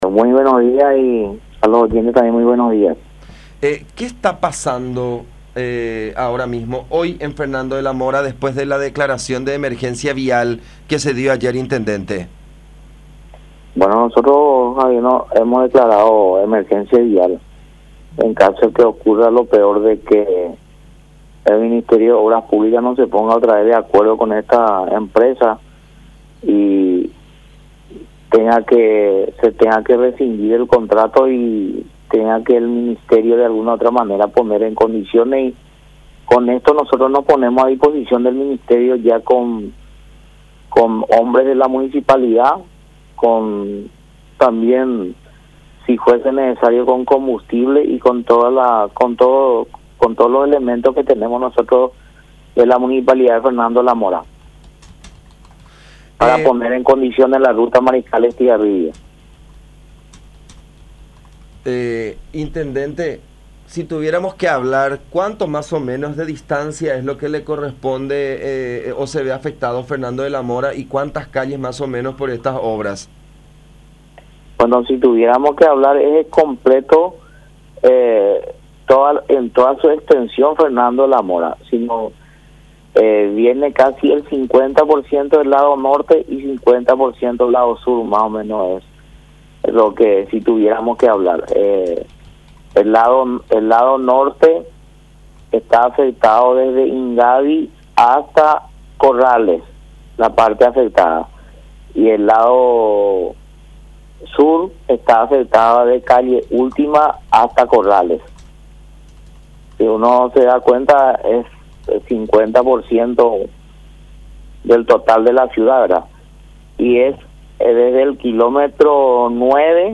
“Si hace falta que utilicemos personal de la municipalidad para ayudar al ministerio en caso de que ocurra lo peor, nosotros ya estamos previendo como fernandinos. Lo que le decimos al gobierno es que estamos dispuestos a poner hombres”, destacó el jefe comunal en comunicación con La Unión.
06-INTENDENTE-ALCIDES-RIVEROS.mp3